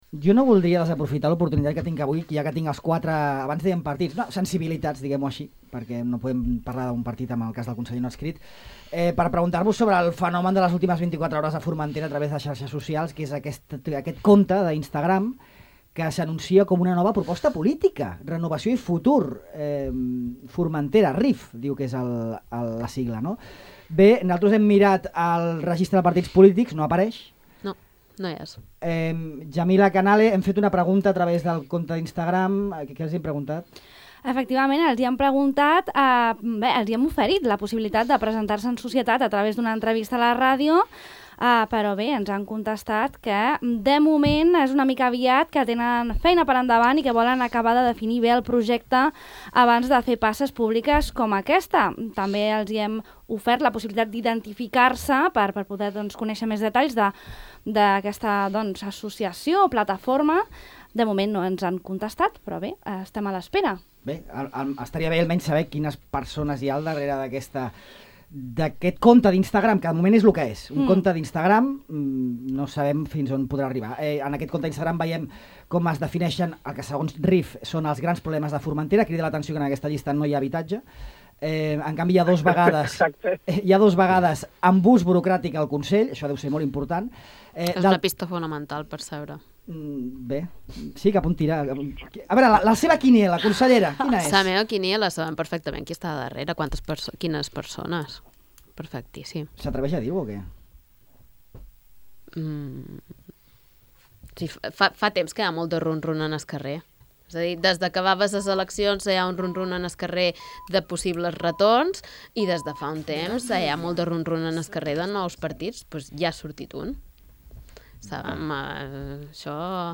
Al final de la Tribuna Oberta d’avui, s’ha preguntat als representants del Ple sobre aquest anunci a la xarxa social.